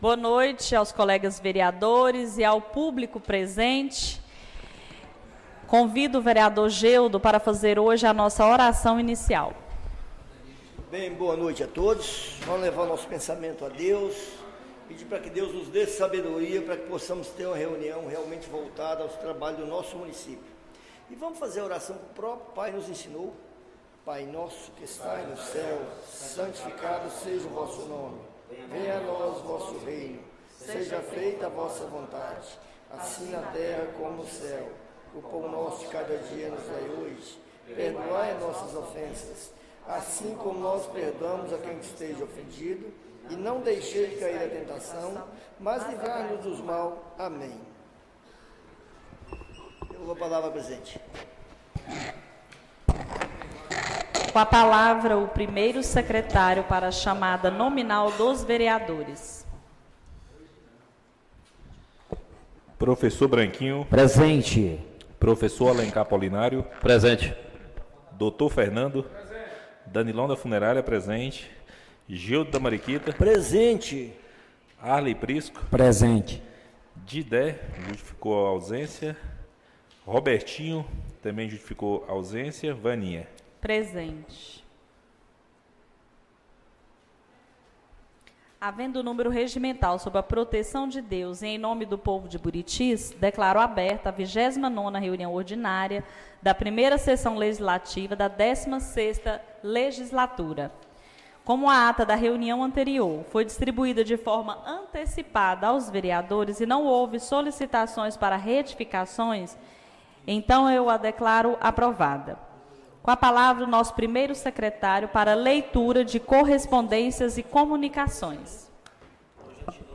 29ª Reunião Ordinária da 1ª Sessão Legislativa da 16ª Legislatura - 01-09-25